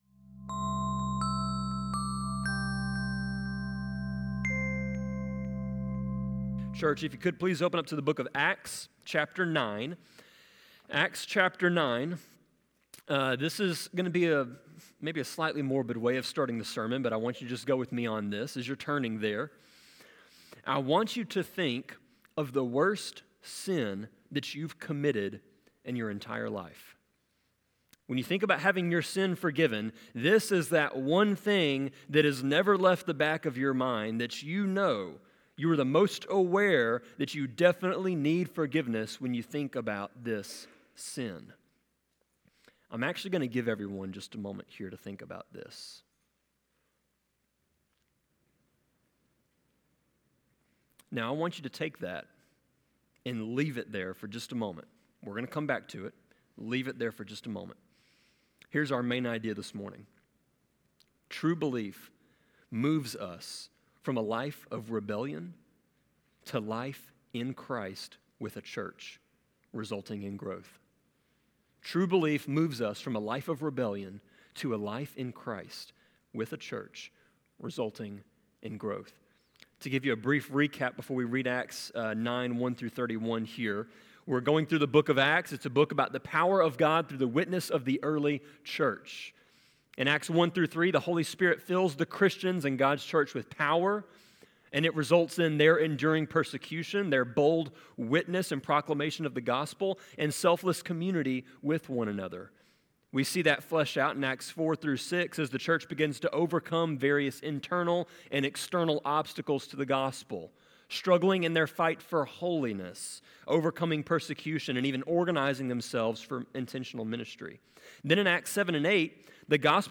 Sermon-24.4.21.m4a